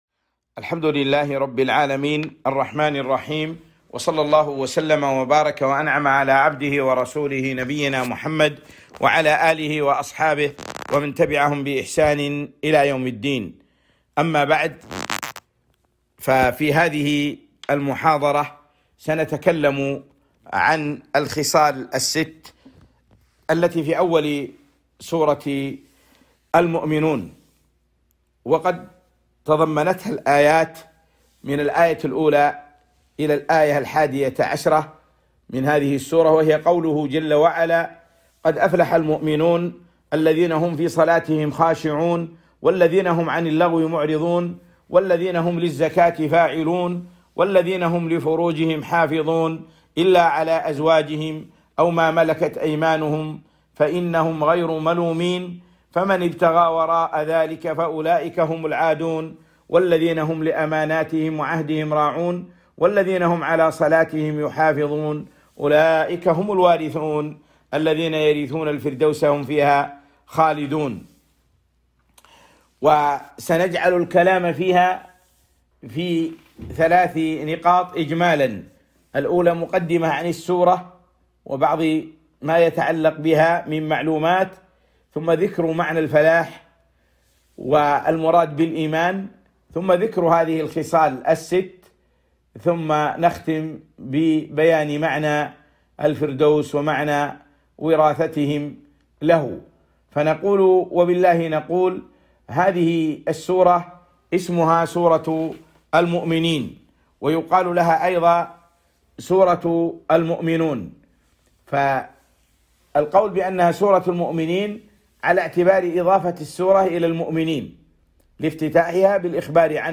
محاضرة - الخصال الست في أول سورة المؤمنين